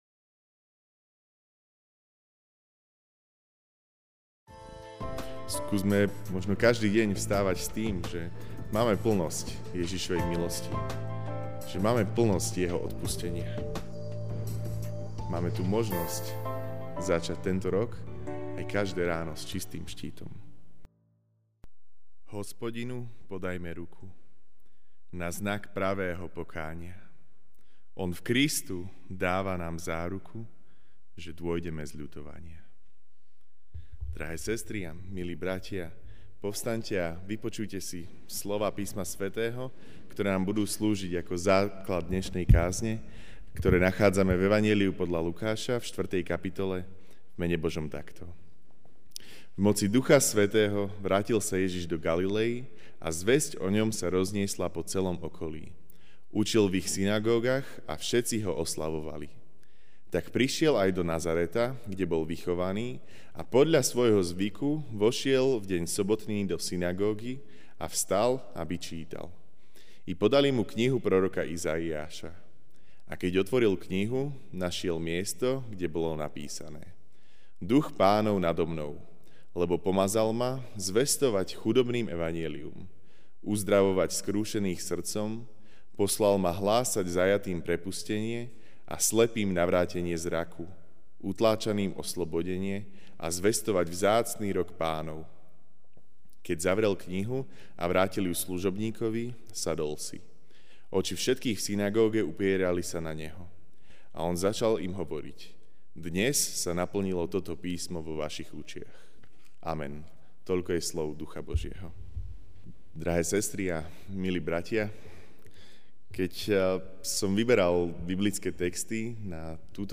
Večerná kázeň: Začiatok s čistým štítom (Lk. 4, 14-21) Učil v ich synagógach a všetci Ho oslavovali.